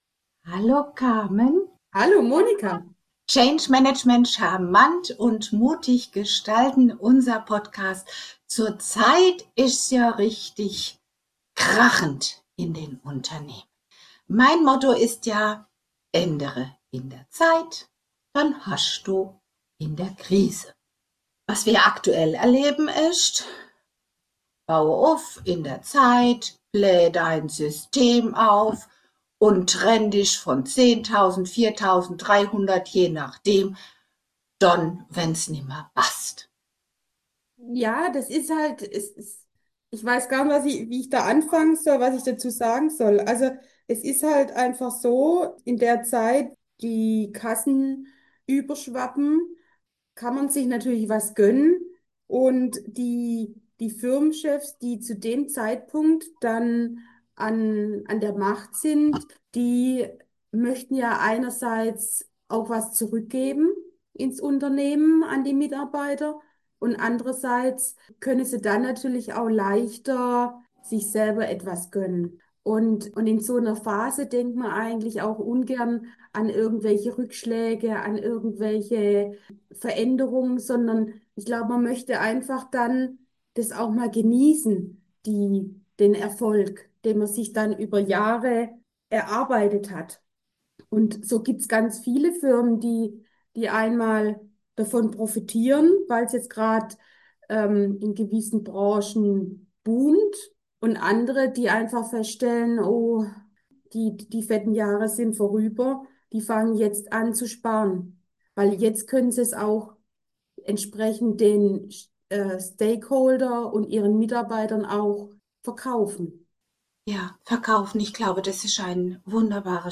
Es ist eine kontroverse, aber wichtige Diskussion über Versäumnisse, Mut zur Veränderung und den richtigen Zeitpunkt für Entscheidungen.